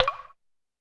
Drums_K4(59).wav